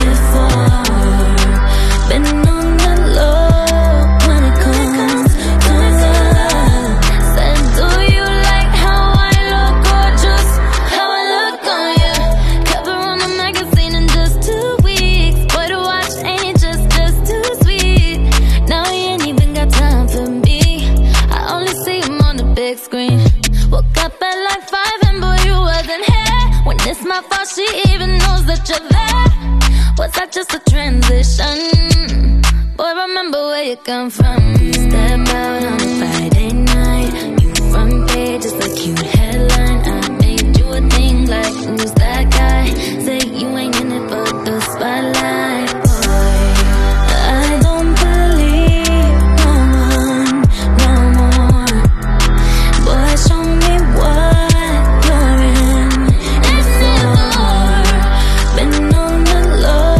BackingVocals/Adlibs Mix Version